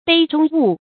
杯中物 注音： ㄅㄟ ㄓㄨㄙ ㄨˋ 讀音讀法： 意思解釋： 杯子中的東西，指酒。